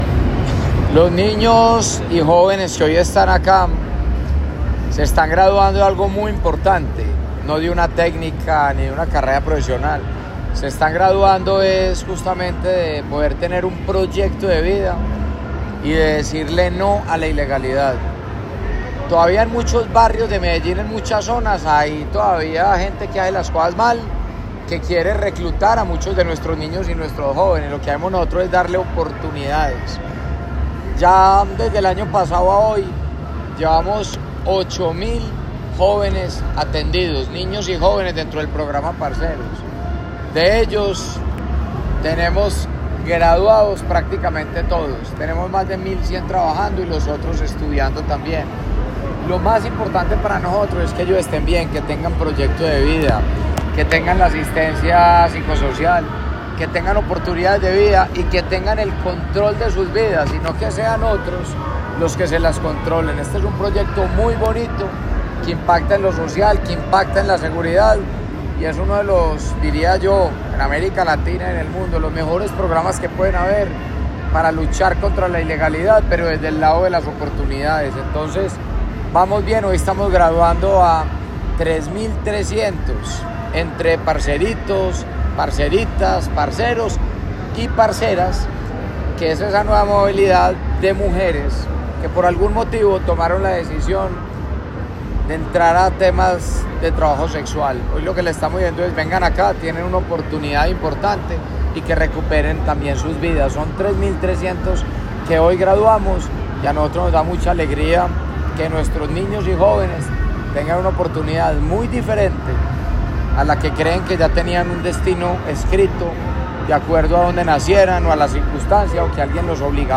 El alcalde Federico Gutiérrez Zuluaga acompañó la graduación de la segunda cohorte de 2025 del Programa Parceros, iniciativa diseñada para proteger a niñas, niños, adolescentes y jóvenes de riesgos como la instrumentalización por parte de estructuras criminales. A la celebración asistieron cerca de 6.000 personas al Centro de Eventos La Macarena, donde se resaltó la disciplina y el compromiso de los beneficiarios durante su proceso.
Declaraciones alcalde de Medellín, Federico Gutiérrez
Declaraciones-alcalde-de-Medellin-Federico-Gutierrez.mp3